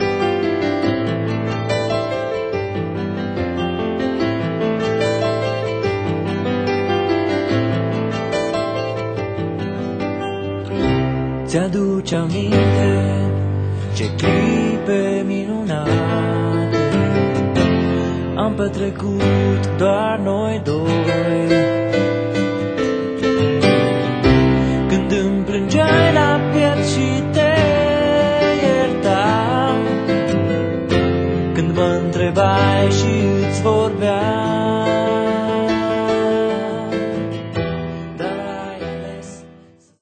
Un album DINAMIC dar cald totodata